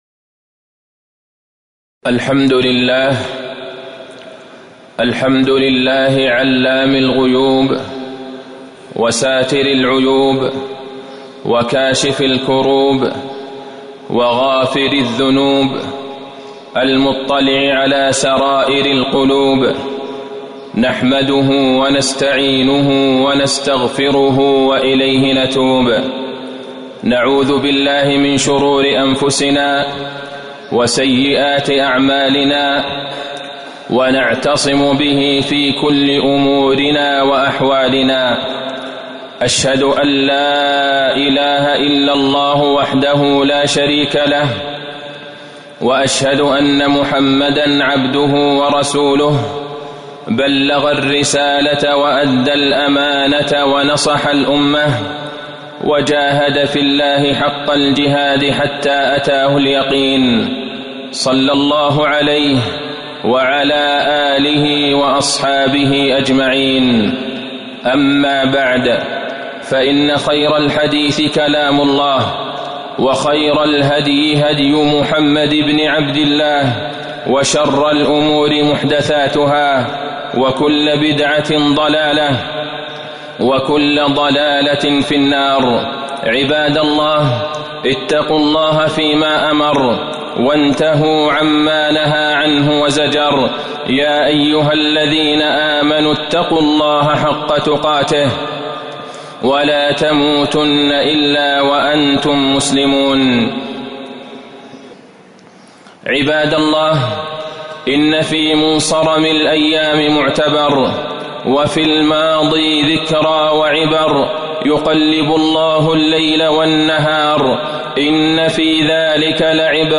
تاريخ النشر ٩ ربيع الثاني ١٤٤١ هـ المكان: المسجد النبوي الشيخ: فضيلة الشيخ د. عبدالله بن عبدالرحمن البعيجان فضيلة الشيخ د. عبدالله بن عبدالرحمن البعيجان الشتاء ربيع المؤمنين